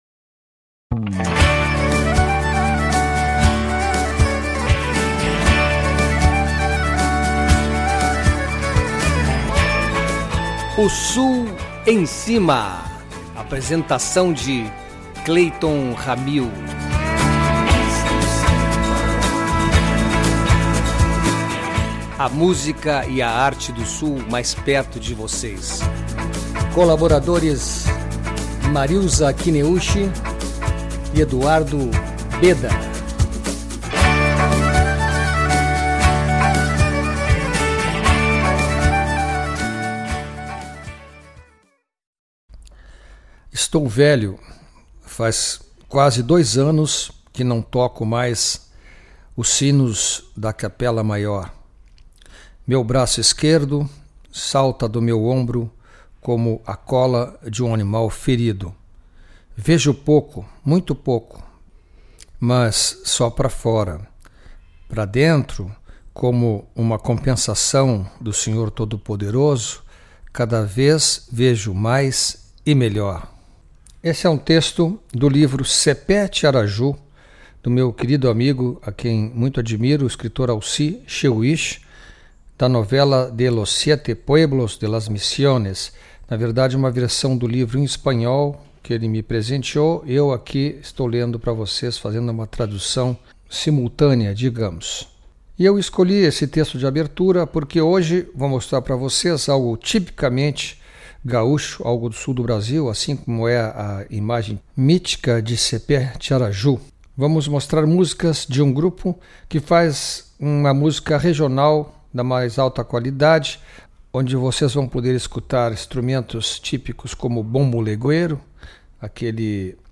quatro vozes que quando juntas soam como um canto único
violão e voz
percussão e bateria
Baixo e Voz
acordeon e voz